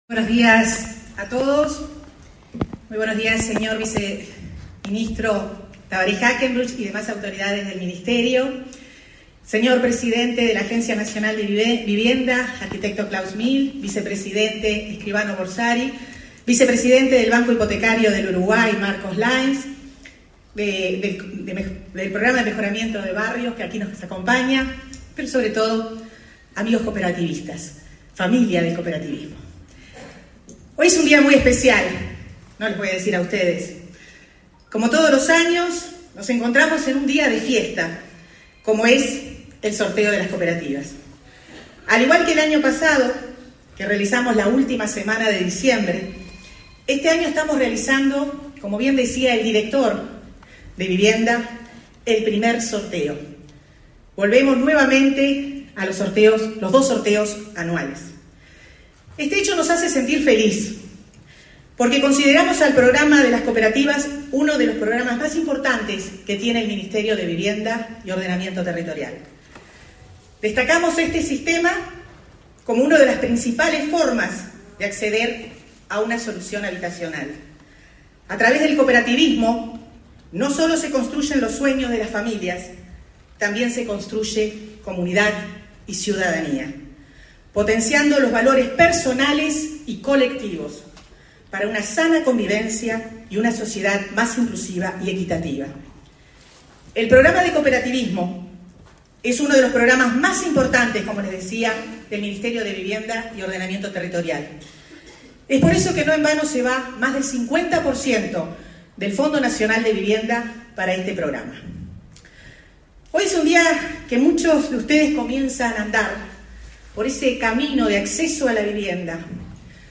Palabras de la ministra de Vivienda y Ordenamiento Territorial, Irene Moreira
La ministra de Vivienda y Ordenamiento Territorial, Irene Moreira, participó, este 26 de julio, en el primer sorteo de este año de cupos para la